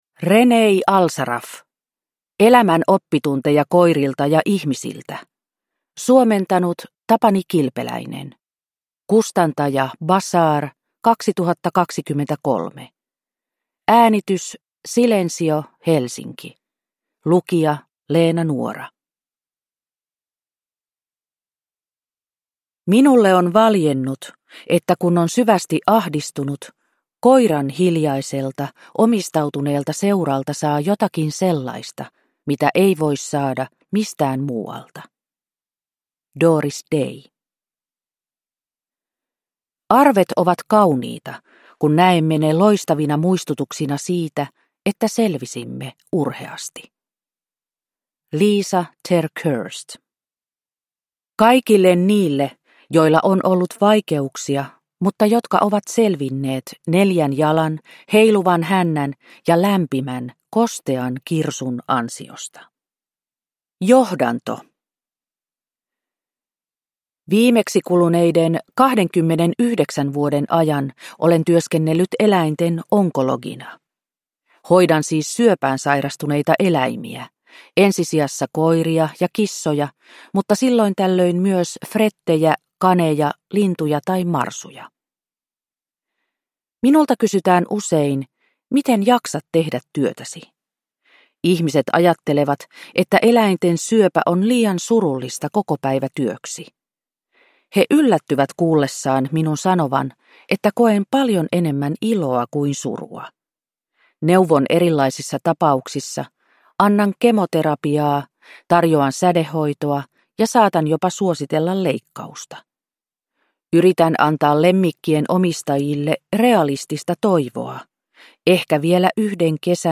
Elämän oppitunteja koirilta ja ihmisiltä – Ljudbok – Laddas ner